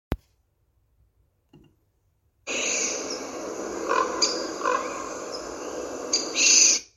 Bird Aves sp., Aves sp.
NotesSīlis, buteo buteo vai tomēr kāds cits? (tas ķērciens, kas krauklim fonā)